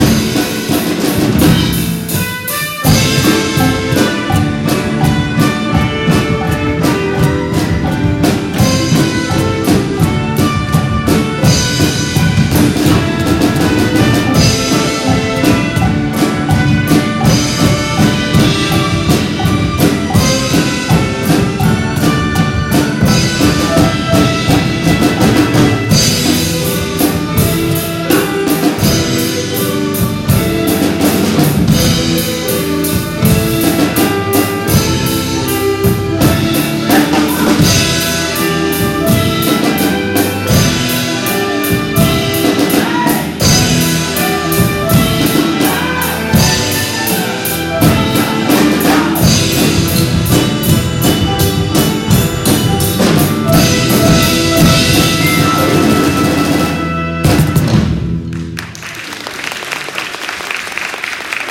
そして、ピアノ、チェロ、バイオリン、トランペット、サックス、パーカッションで、たくさんの曲を披露してくださいました。